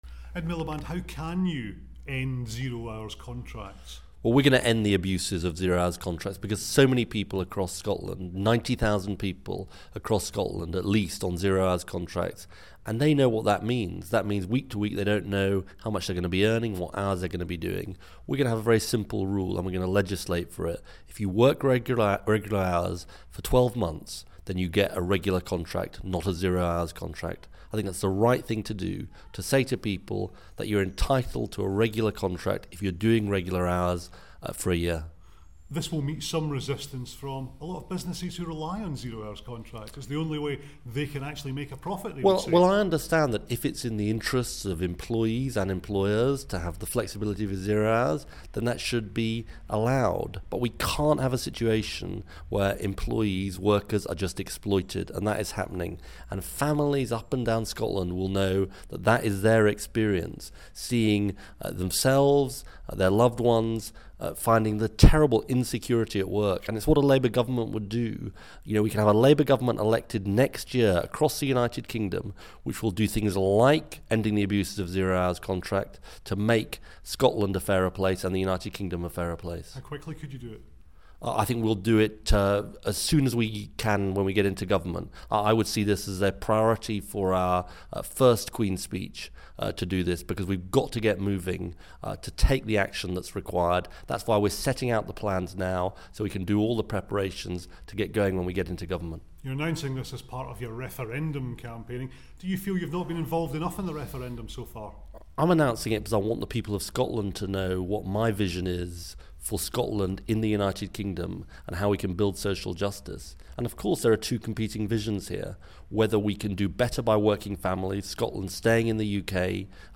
During a campaigning visit to Scotland